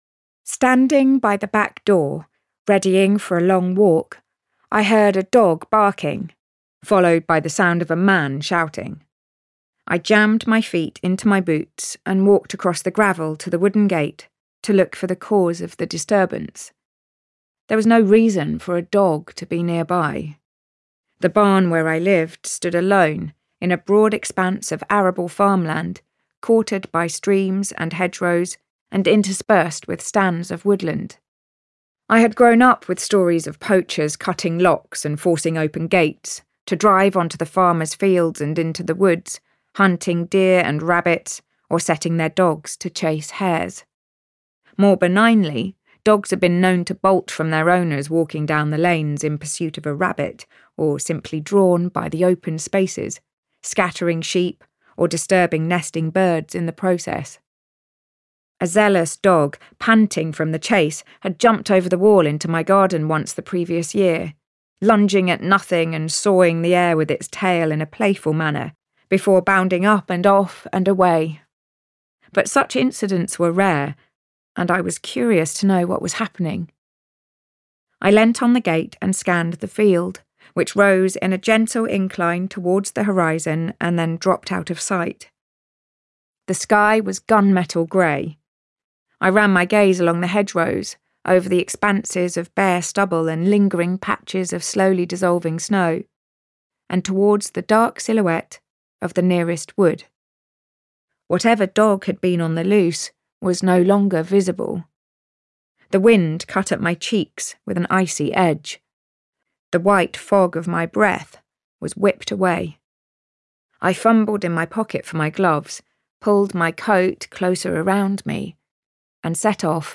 Audiobook sample
Raising-Hare-Sample.mp3